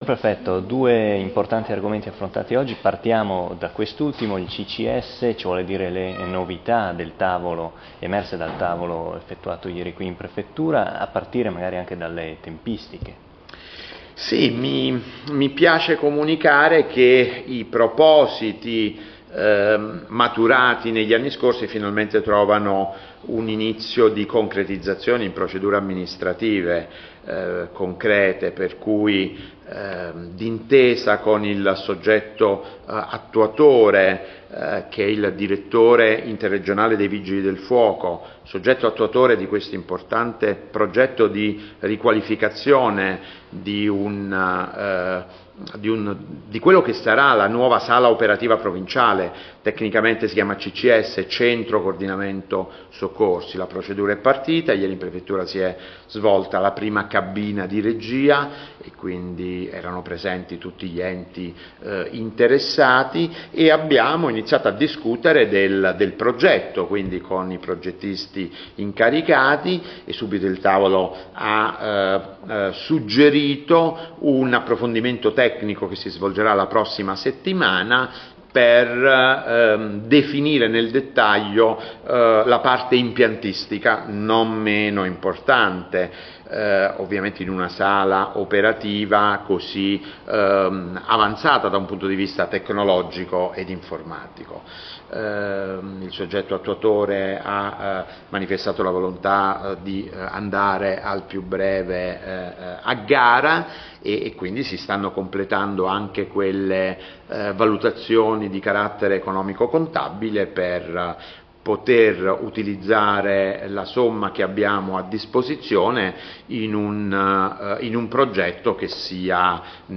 IL PREFETTO MARIANO SAVASTANO punto stampa